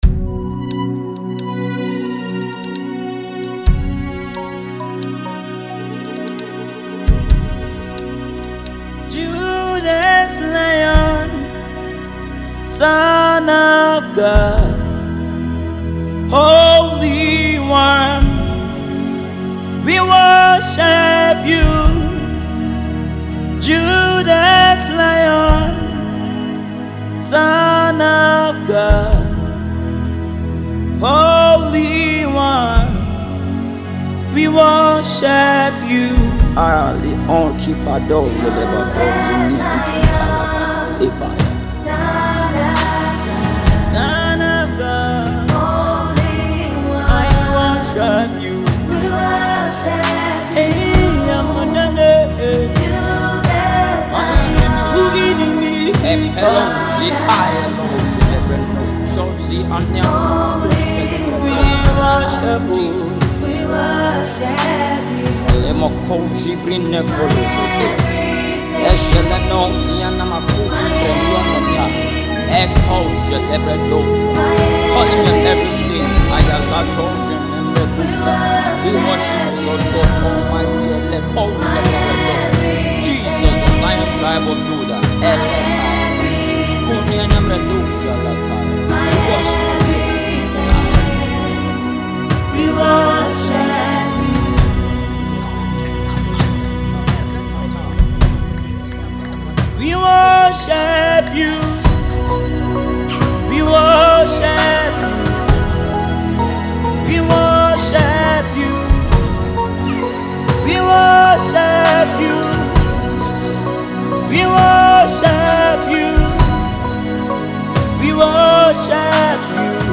spirit-filled song